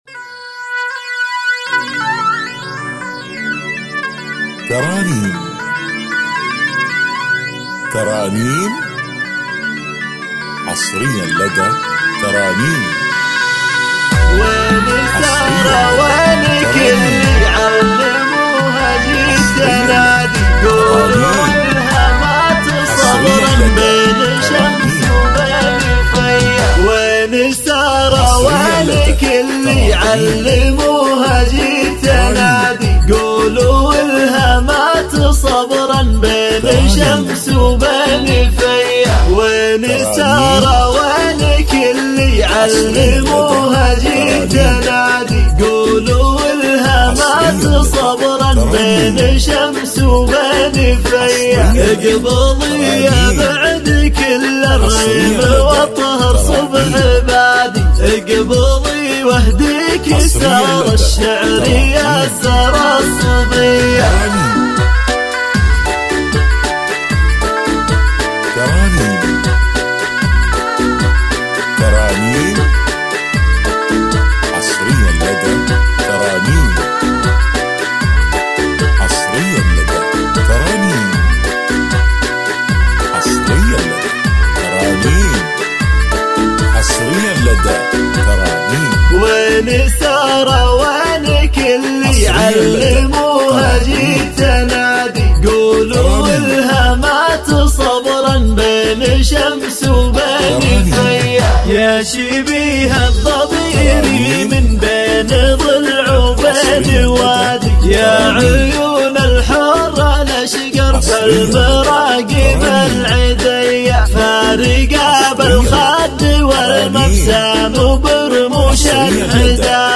بدون موسيقى